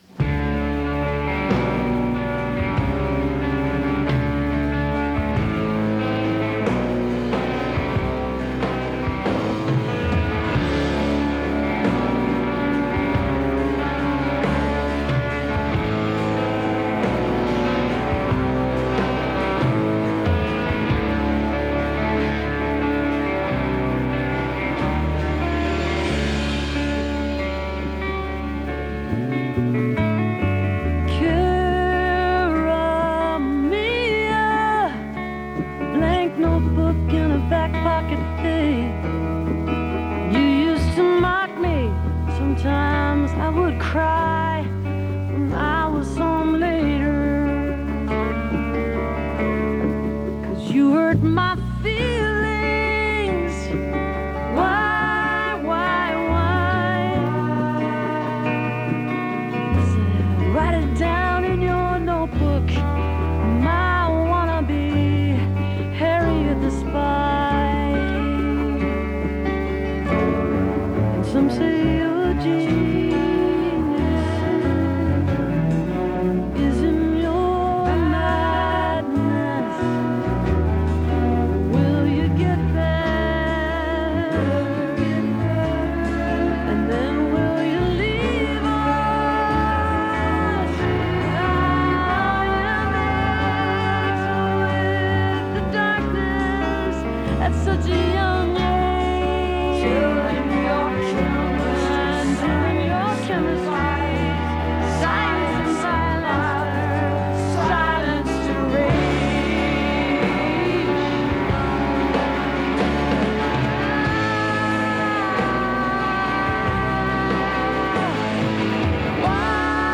album version